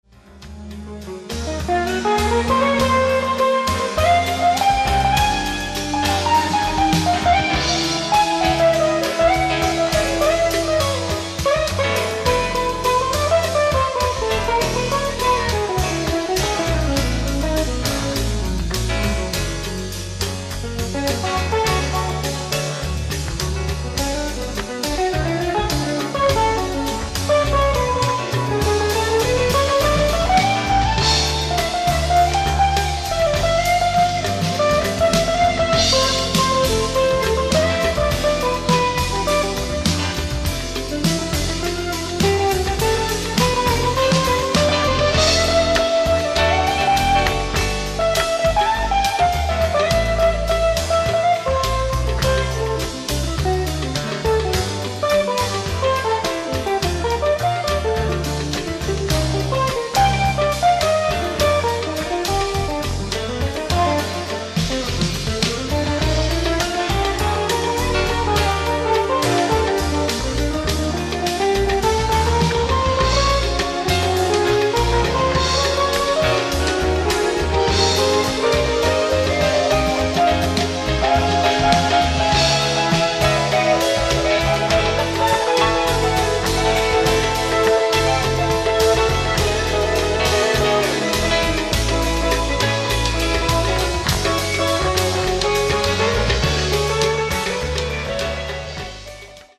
ライブ・アット・Ｕポート・簡易保険ホール、東京 11/12/1983